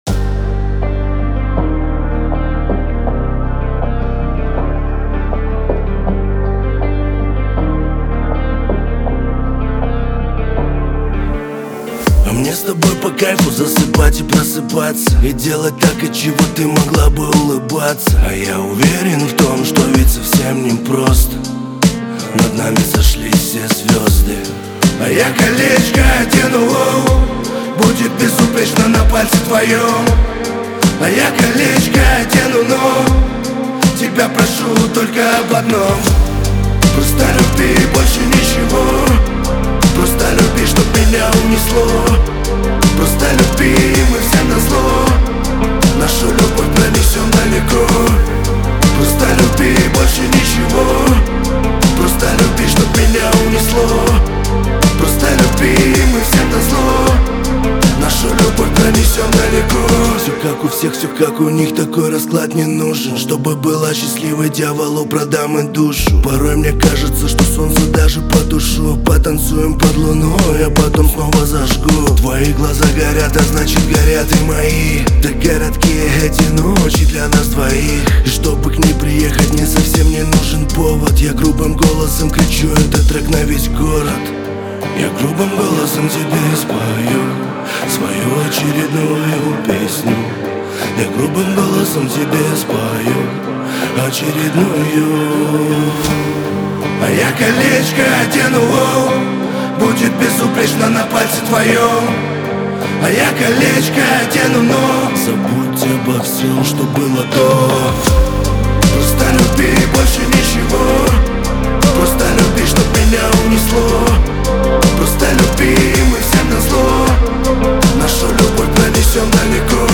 Шансон , Лирика